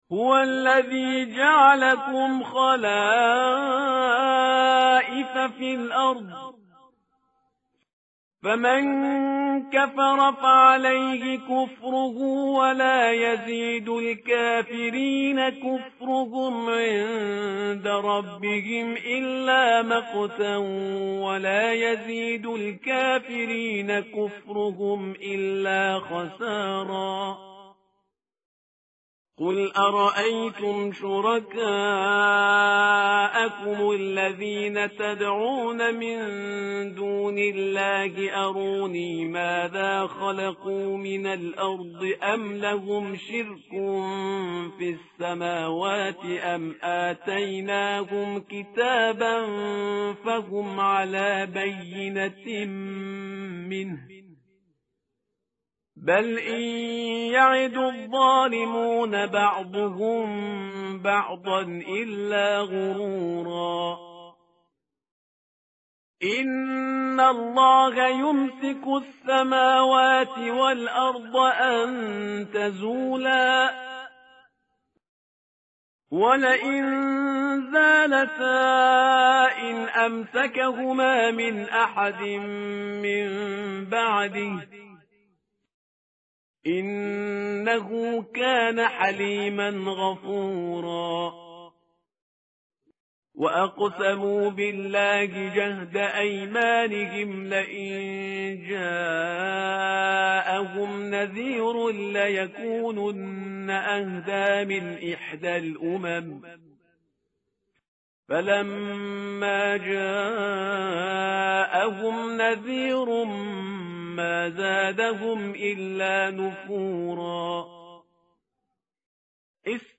tartil_parhizgar_page_439.mp3